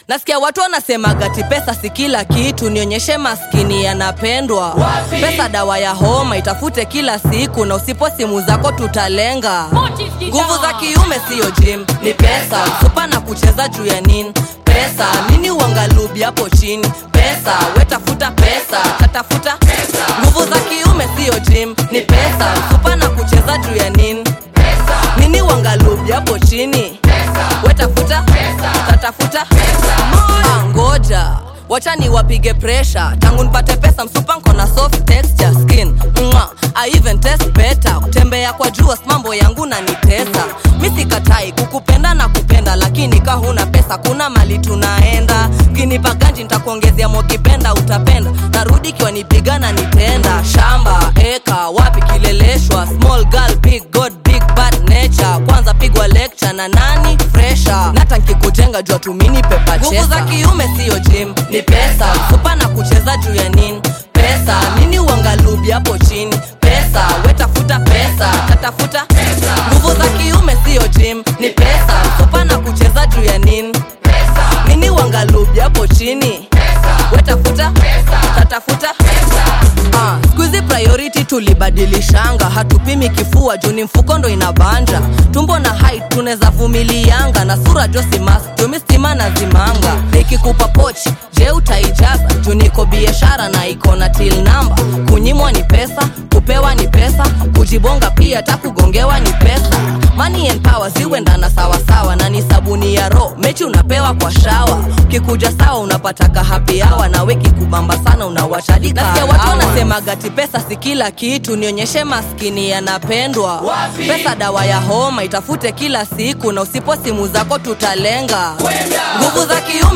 Kenyan female rapper
East African hip-hop
Through sharp lyrics and confident delivery